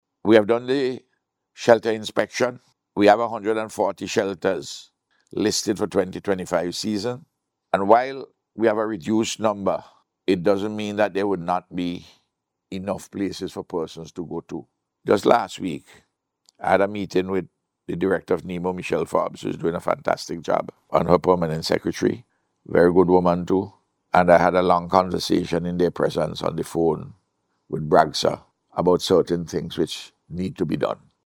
That’s according to Prime Minister, Dr. Ralph Gonsalves who made the announcement during his official message to mark the start of this year’s hurricane season